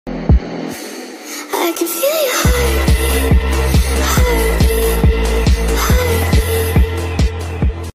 heartbeat..